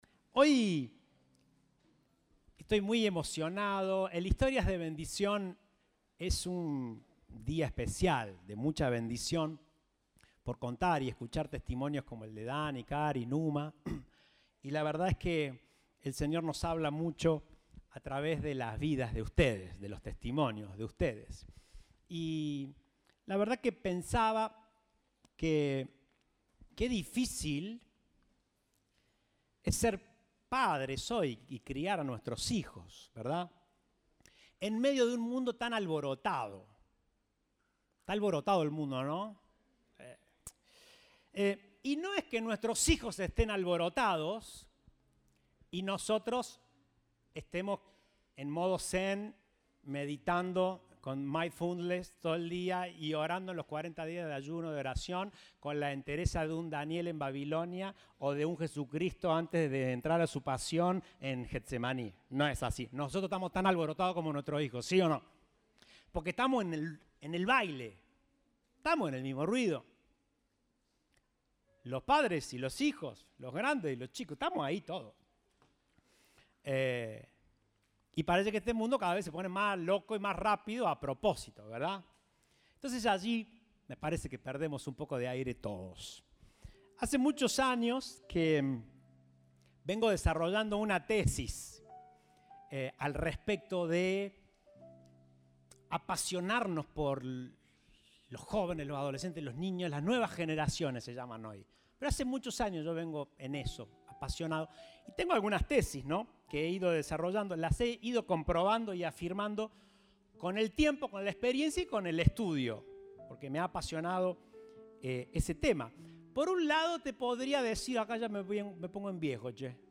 Compartimos el mensaje del Domingo 12 de Marzo de 2023